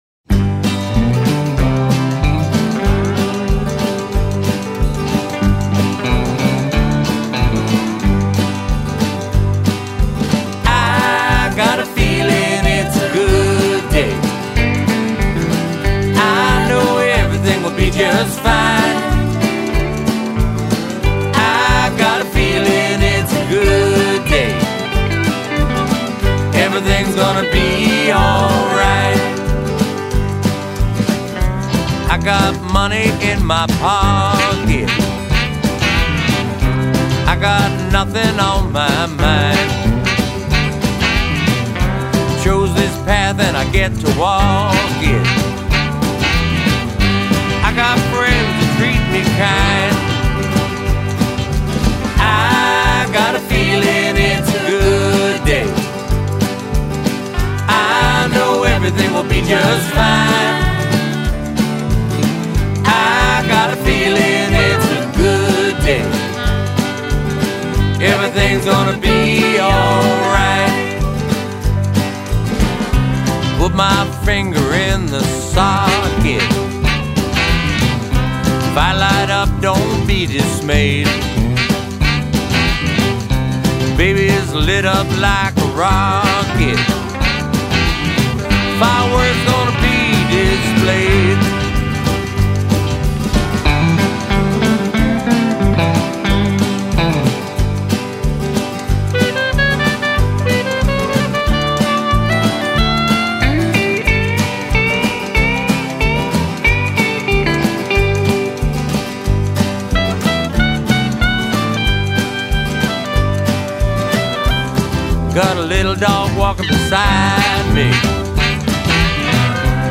keys
drums, backup vocals
percussion
bass
sax, flute